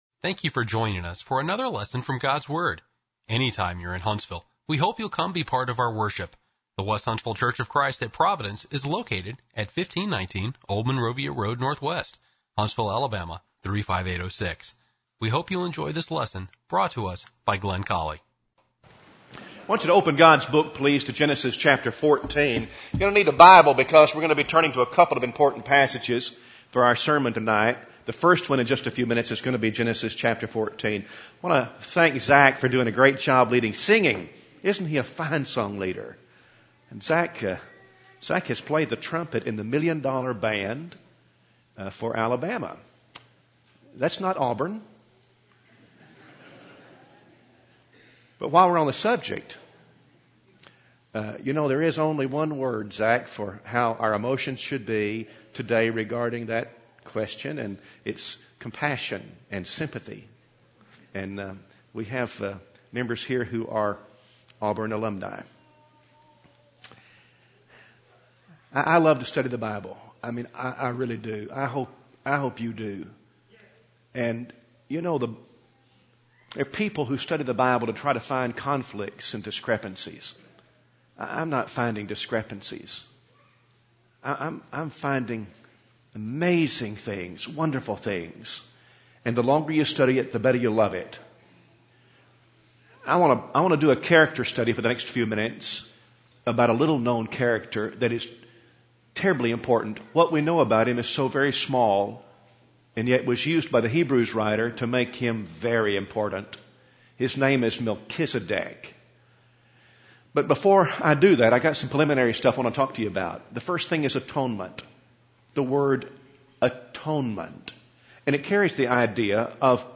Enjoy Melchizedek sermon from the West Huntsville church of Christ pulpit.
Sermon Audio